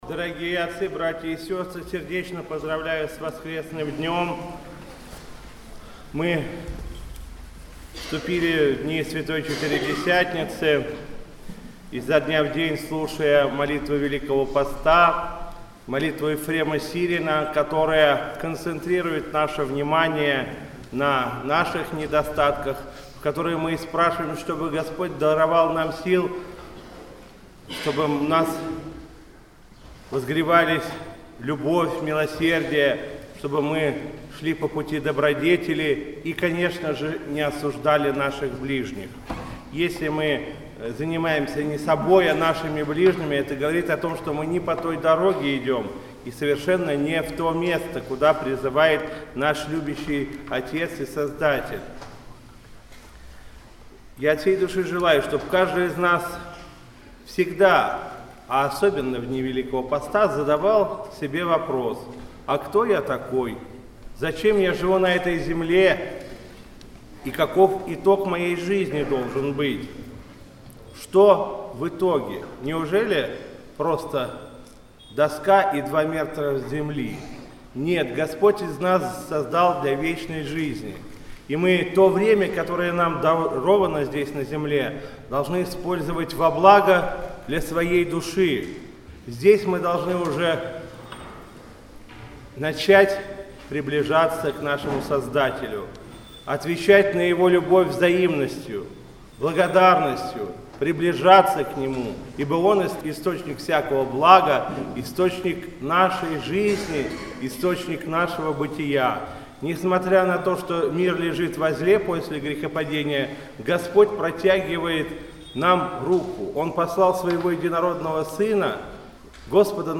По окончании богослужения правящий архиерей Вологодской епархии обратился к верующим со словом проповеди.